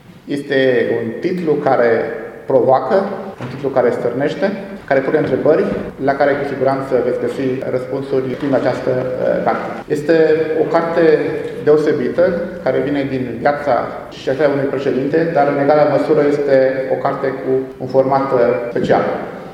Universitatea de Medicină și Farmacie din Tîrgu-Mureș a găzduit azi evenimentul de lansare a cărții „Prețul demnității. O istorie altfel”, scrisă de Laura Ganea.